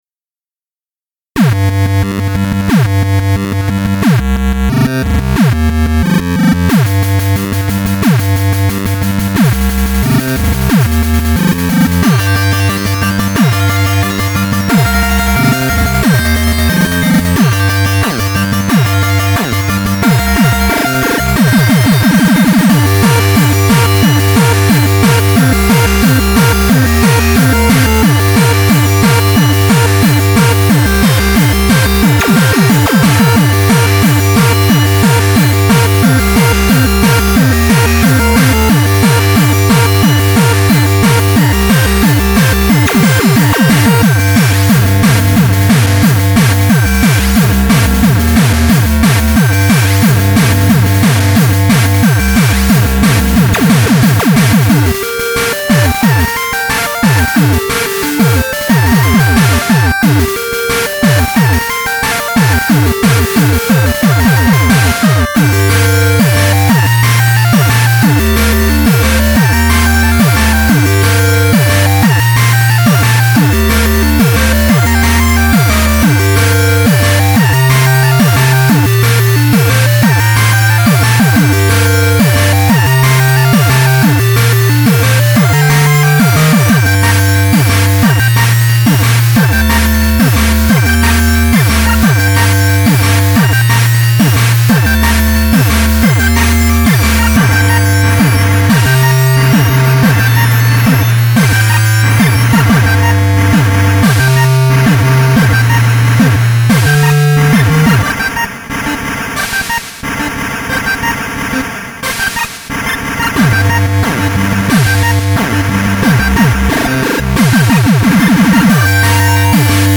still haven't written an ending. just that and a bit of mud-clean-up and it should be good to go. wait? you have some cc to give me before i call it 'finished?' well lemme have it!